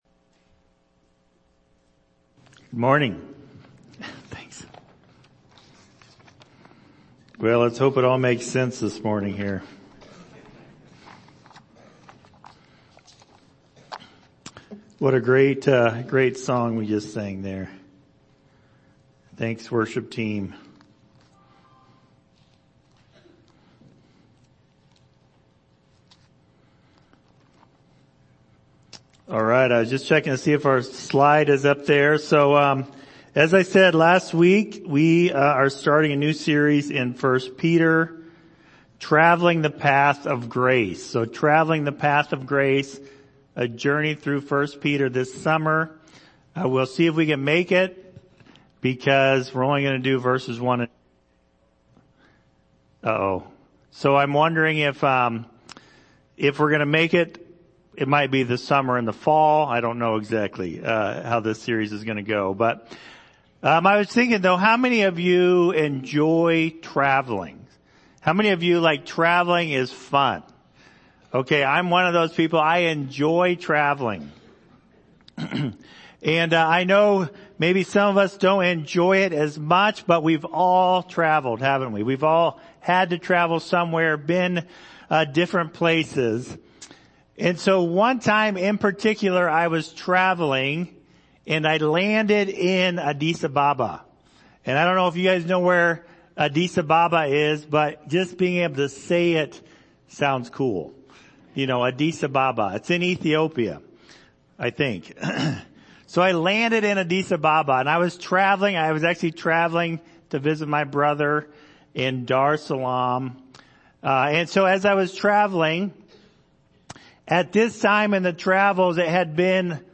1 Peter Passage: 1 Peter 1:1-2 Service Type: Sunday Morning « Peter